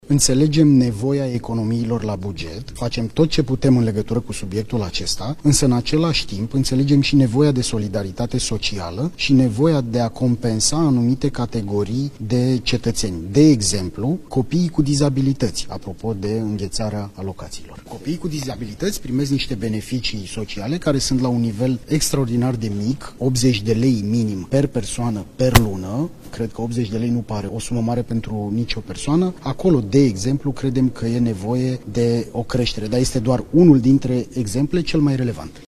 Ministrul Muncii, Florin Manole: „Copiii cu dizabilități primesc niște alocații sociale care sunt la un nivel extraordinar de mic”